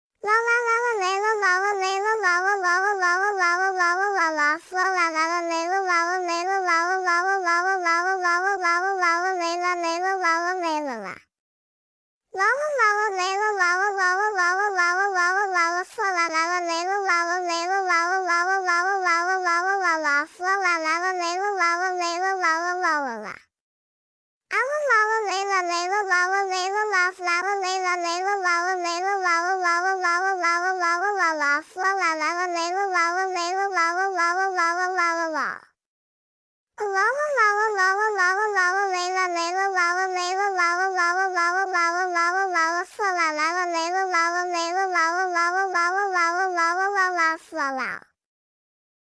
babay voice
Category 🗣 Voices
Alarm Alert Annoying babay Cartoon Funny High Pitch sound effect free sound royalty free Voices